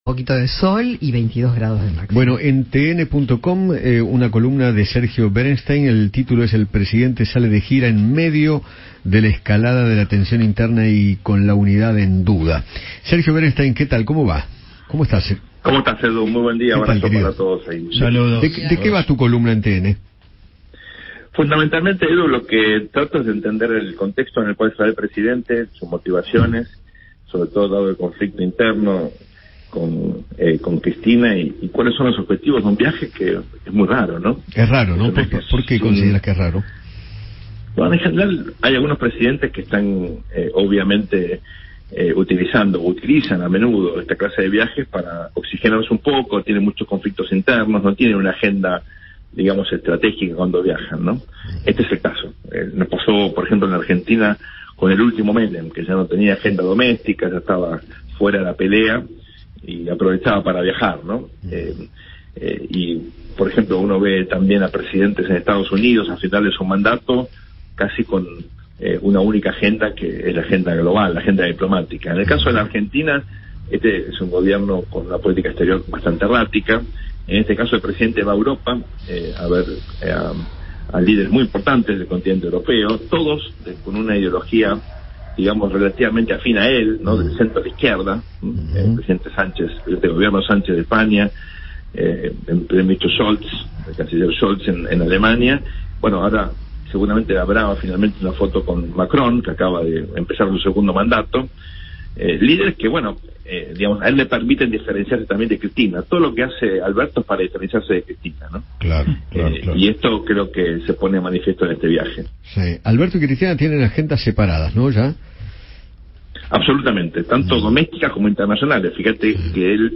El analista político Sergio Berensztein dialogó con Eduardo Feinmann sobre la gira europea de Alberto Fernández, en medio de la interna del oficialismo, y sostuvo que “hay algunos presidentes que utilizan estos viajes para oxigenarse un poco”.